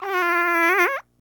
Fart Squeak Sound
human
Fart Squeak